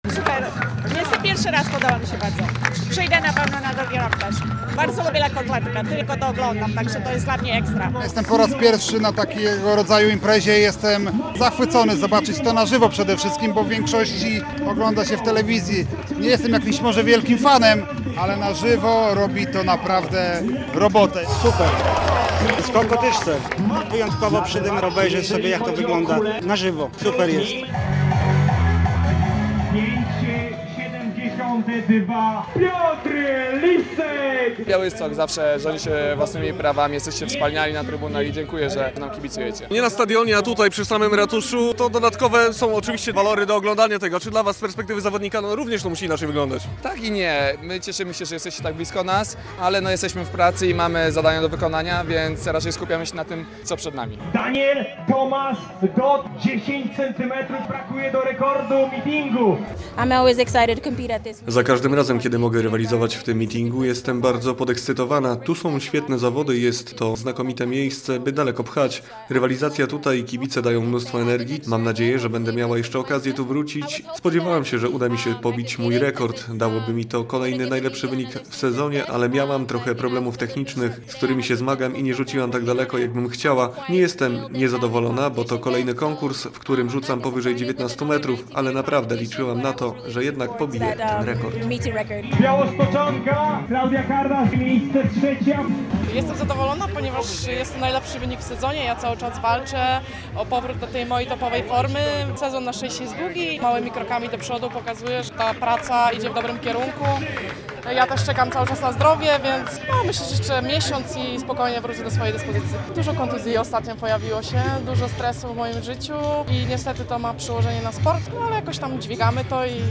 Mityng Gwiazd w Białymstoku - relacja